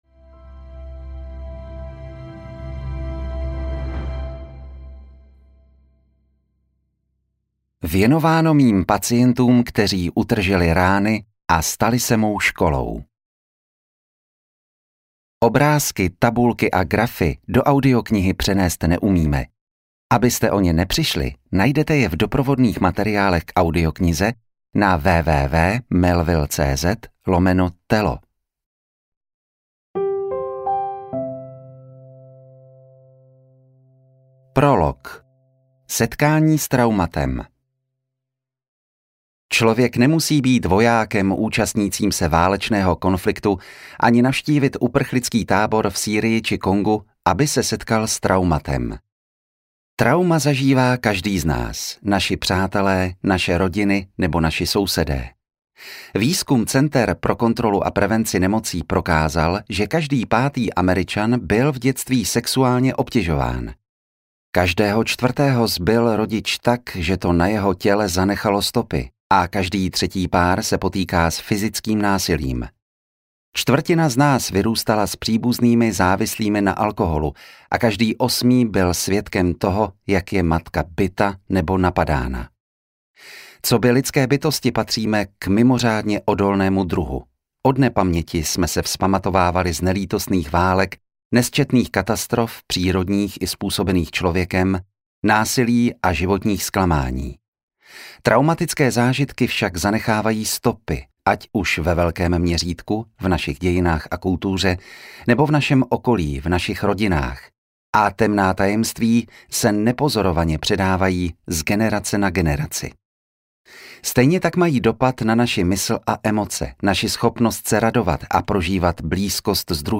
Tělo sčítá rány audiokniha
Ukázka z knihy
telo-scita-rany-audiokniha